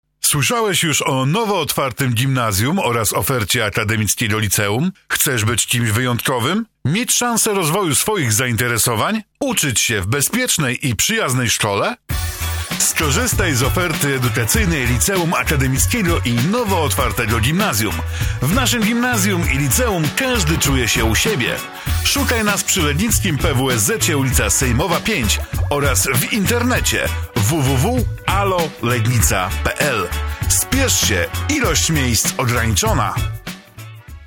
Nasza reklama radiowa… posłuchaj
W tym miejscu możesz posłuchać naszej reklamy radiowej….